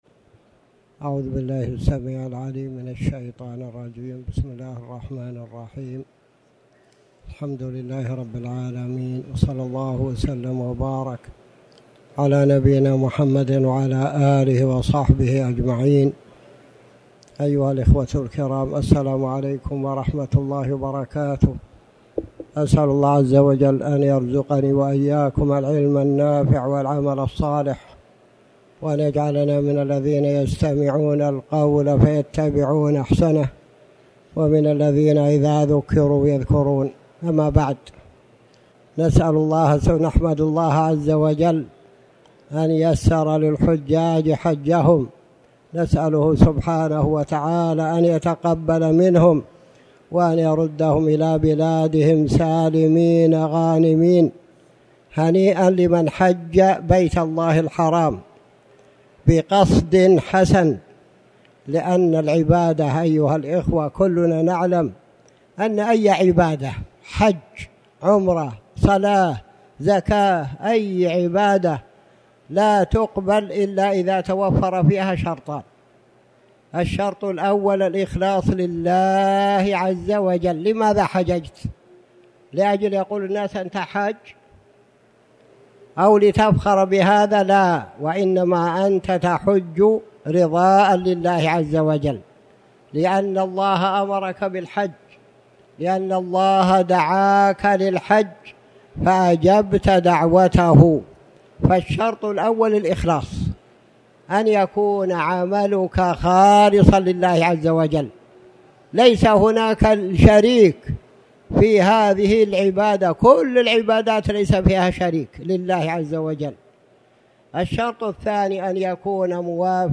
تاريخ النشر ١٥ ذو الحجة ١٤٣٩ هـ المكان: المسجد الحرام الشيخ